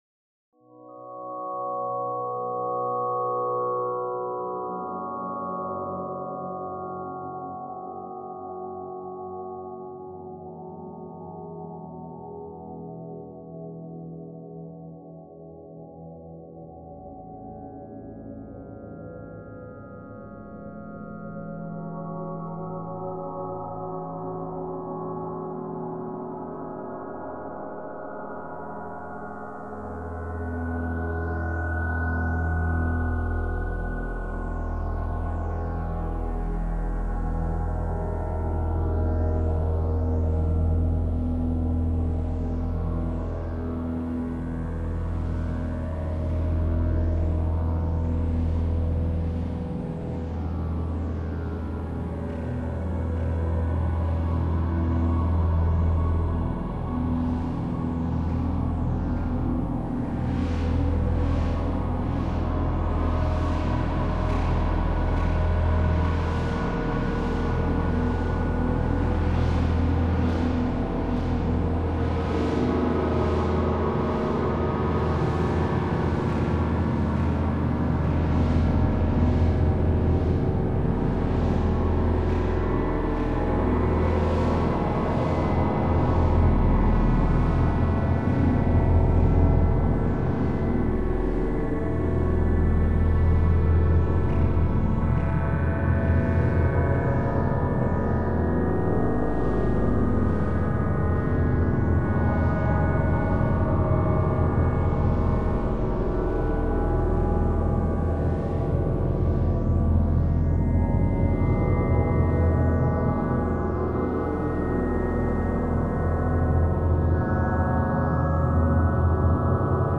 61-Algorithms and ambient
Recently I’ve revisited some material generated several years ago for another ambient/soundscape sort of piece.
The basic raw sound material comes from some experimentation with the KOAN software, using its builtin synthesizer. Also layered over that are some free OLPC samples and field recordings, and drones from the u-he ZebraCM and Cakewalk Dimension synths.